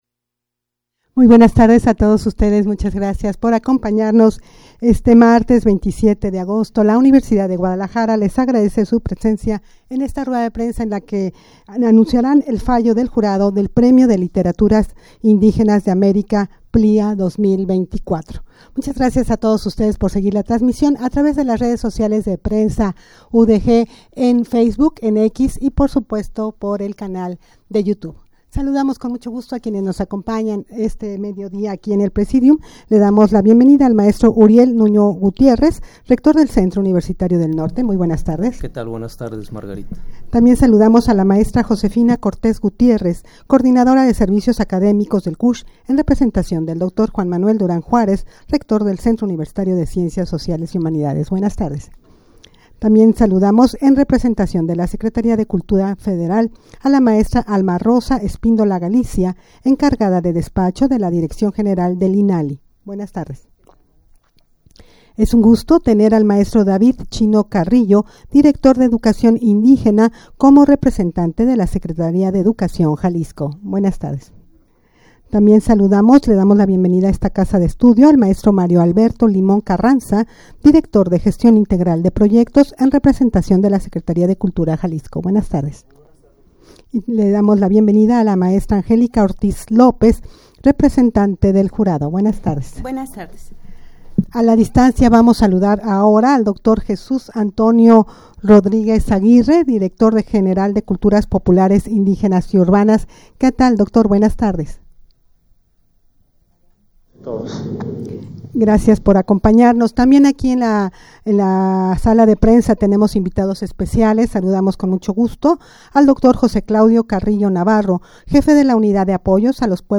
Audio de la Rueda de Prensa
rueda-de-prensa-para-anunciar-el-fallo-del-jurado-del-premio-de-literaturas-indigenas-de-america-plia-2024.mp3